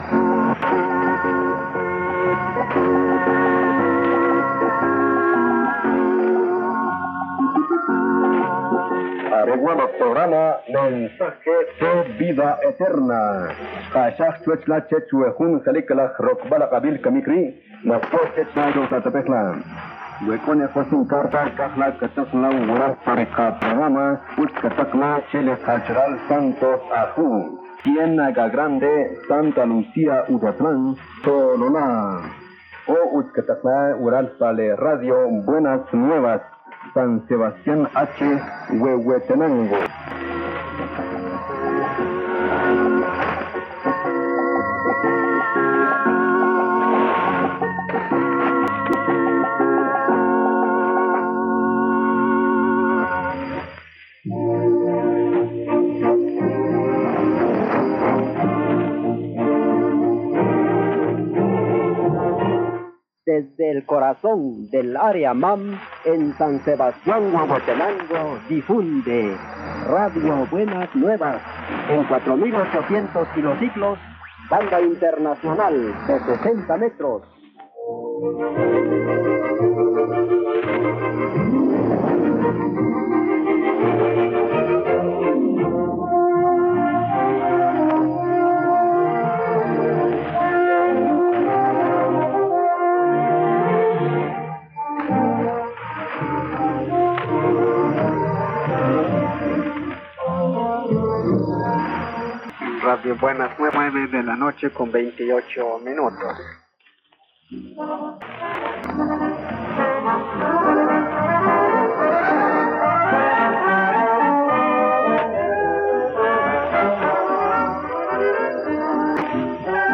here is the Radio Buenas Nuevas sign-off announcement in Mam and then Spanish later that same evening. According to the announcement, since my 1987 visit they had added programming in the Aguacateco, Acateco, Quiché, and Chuj languages: